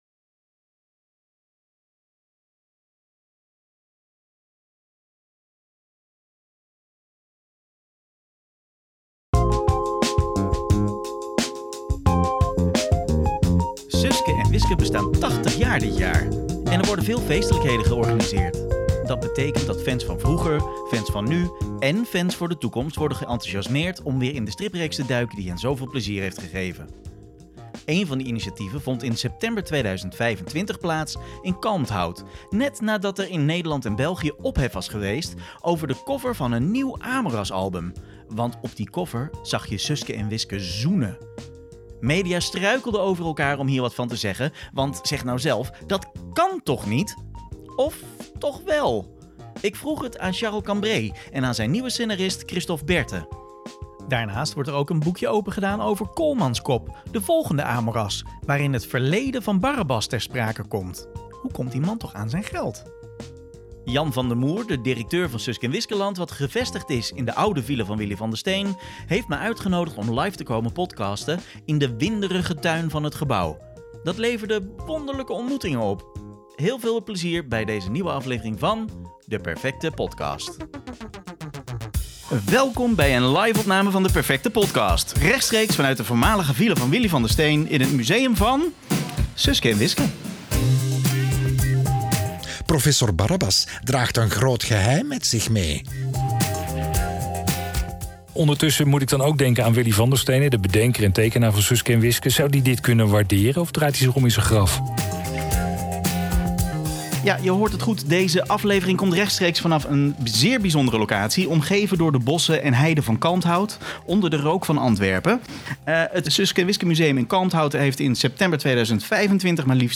In deze reeks gesprekken praat ik met kenners, makers (waaronder een tweeluik met Paul Geerts) en verzamelaars over het verleden, heden en de toekomst van de strips.